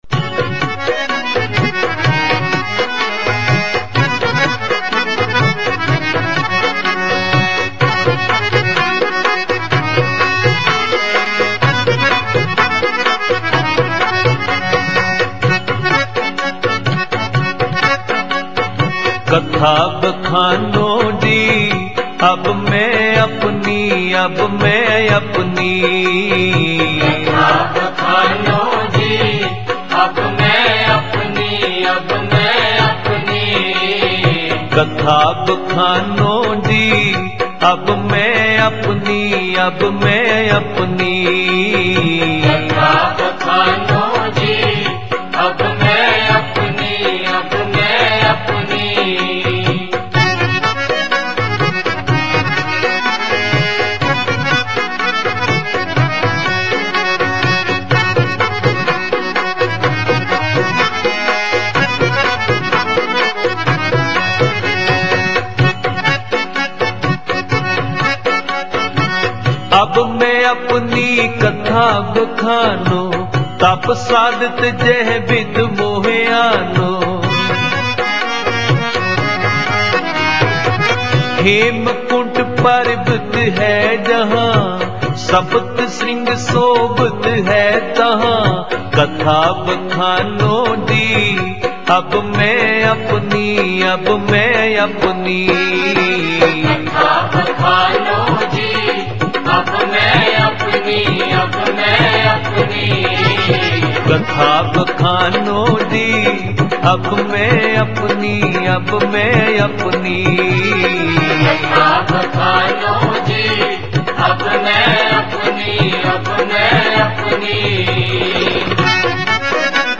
Kirtan with katha
Album: Charnah Gobind Marg Sohava Genre: Gurmat Vichar